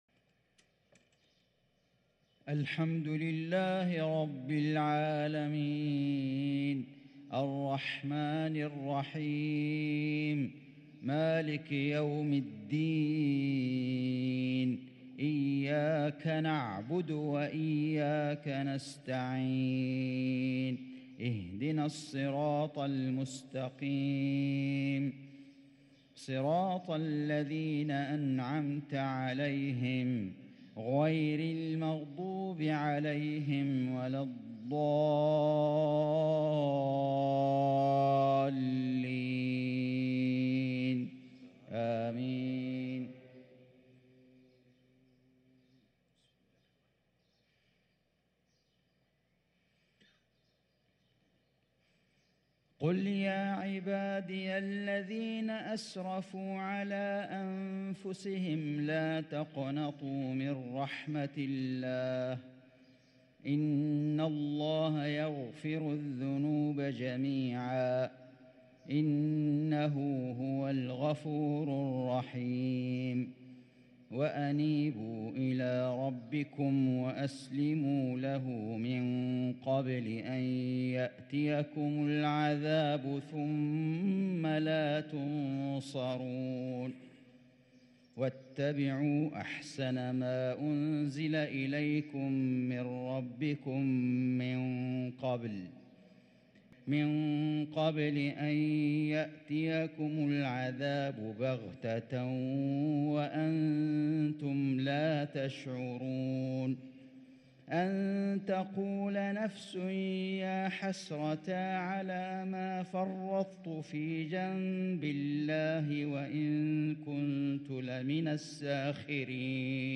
صلاة المغرب للقارئ فيصل غزاوي 28 ربيع الأول 1444 هـ
تِلَاوَات الْحَرَمَيْن .